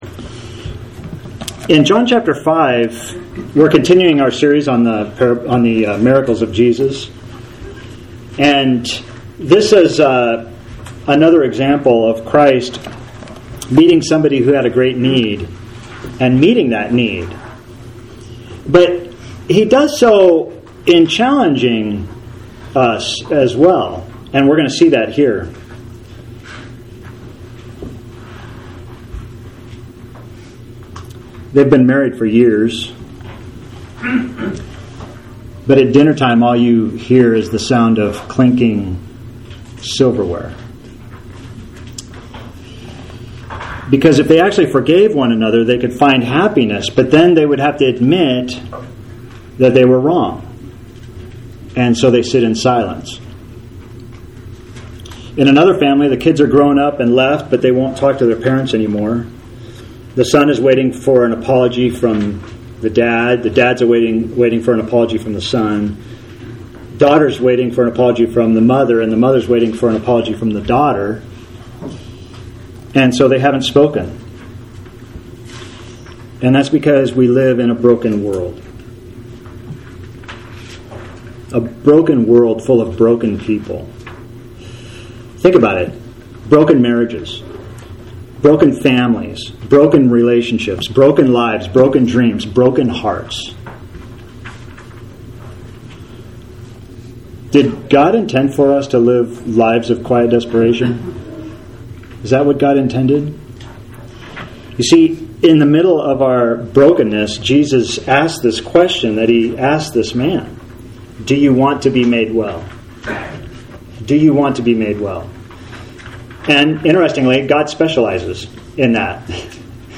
Sermon for Sunday June 14, 2020 – AUDIO | TEXT PDF